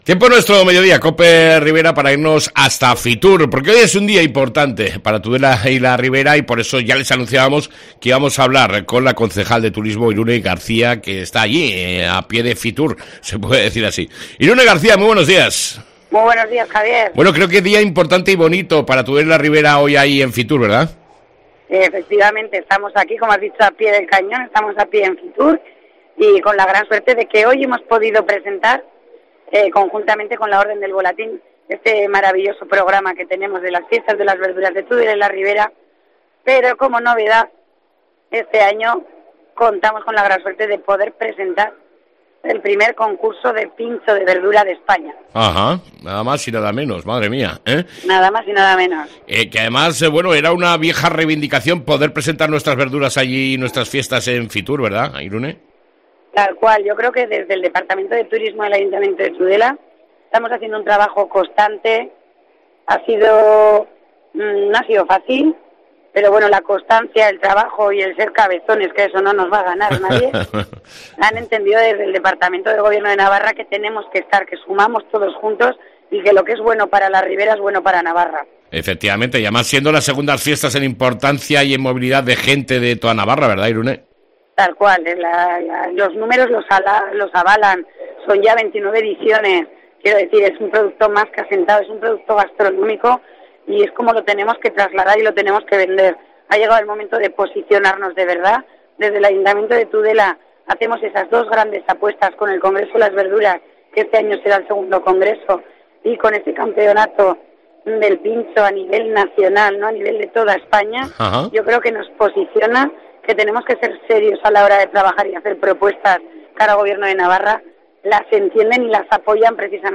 ENTREVISTA CON IRUNE GARCÍA, CONCEJAL DE TURISMO